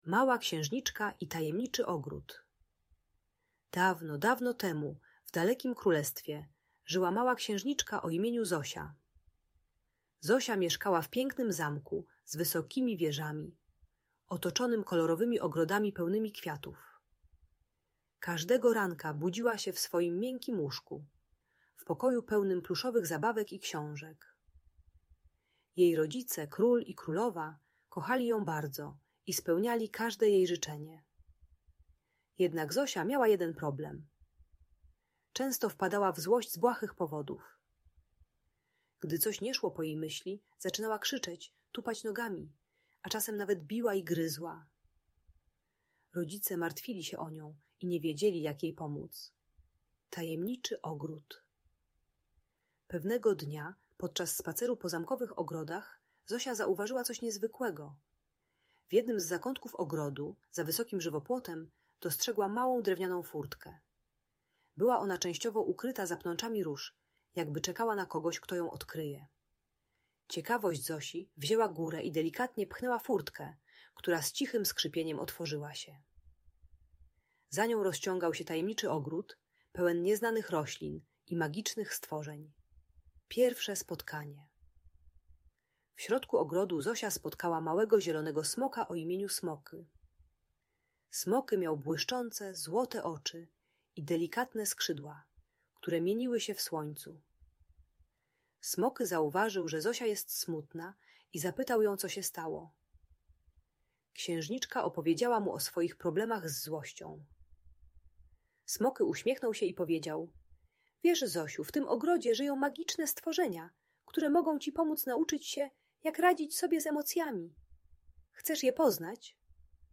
Opowieść o Małej Księżniczce i Tajemniczym Ogrodzie - Audiobajka